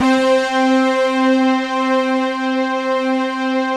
Index of /90_sSampleCDs/Optical Media International - Sonic Images Library/SI1_ObieStack/SI1_OBrass Stabs